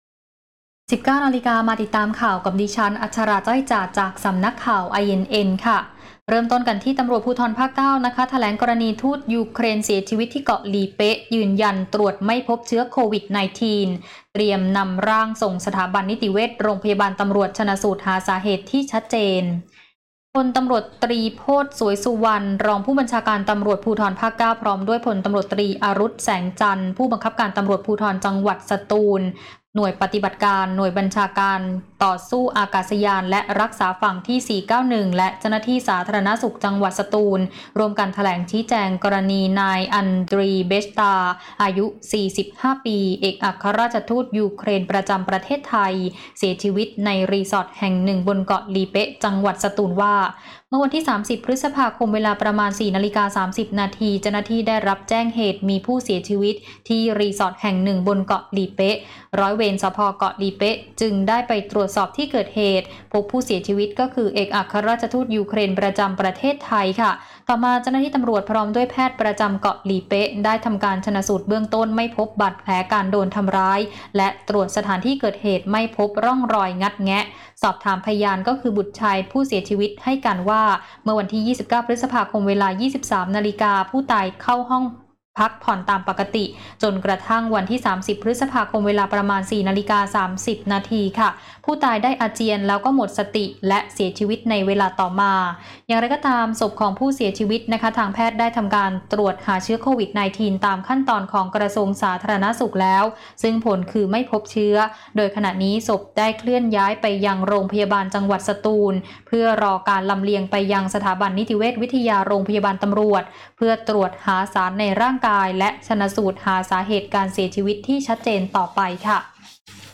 ตร.ภ.9 แถลงกรณีทูตยูเครน เสียชีวิตที่เกาะหลีเป๊ะ ยันตรวจไม่พบเชื้อโควิด
คลิปข่าวต้นชั่วโมง